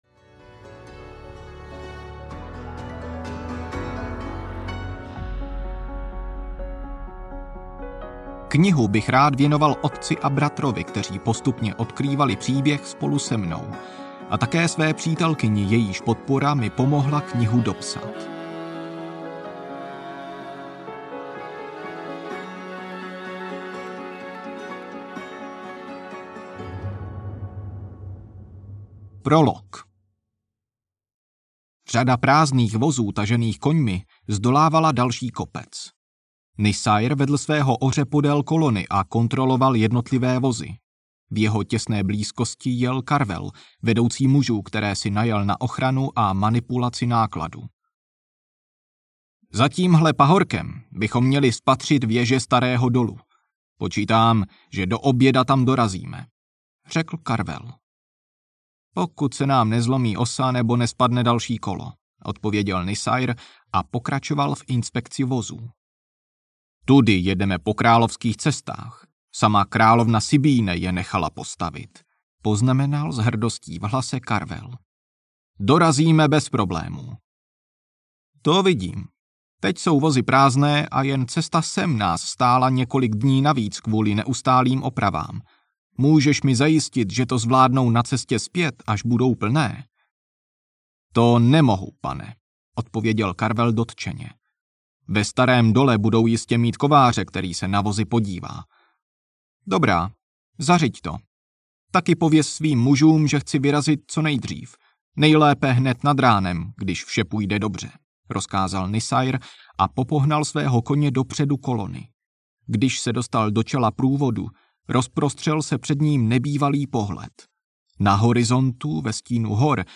Podzimní bouře audiokniha
Ukázka z knihy